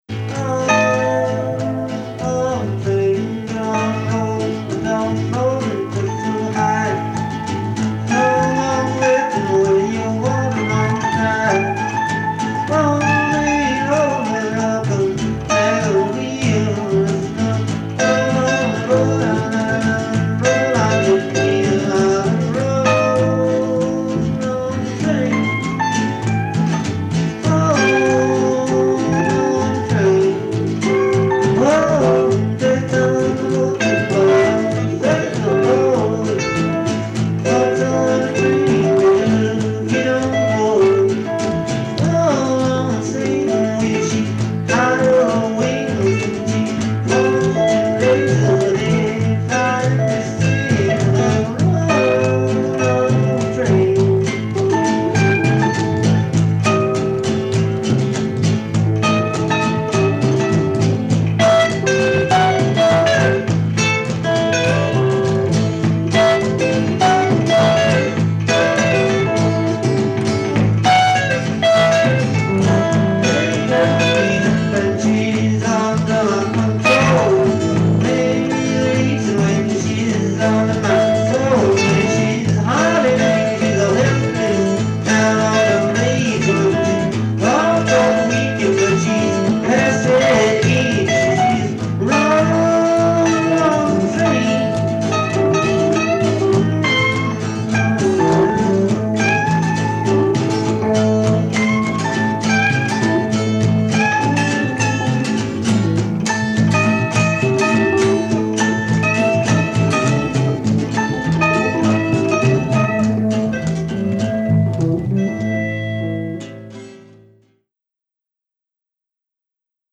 Wen maar even aan het huiskamergeluid. klik